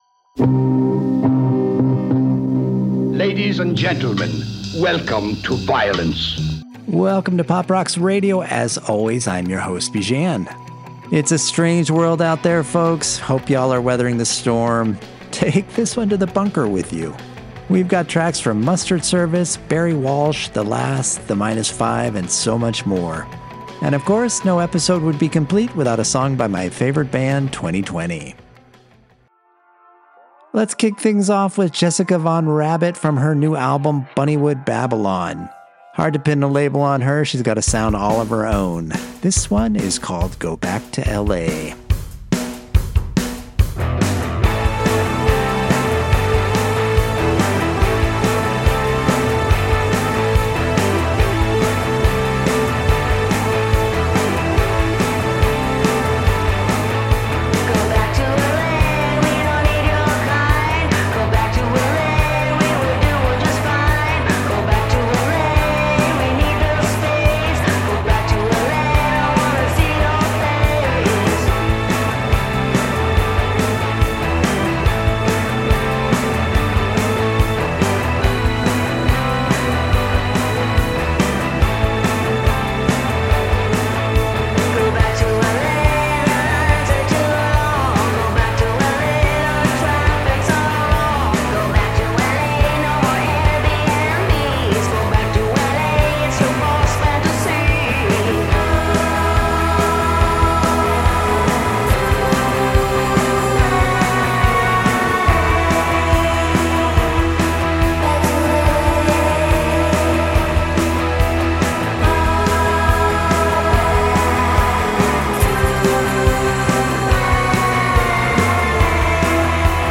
mixtape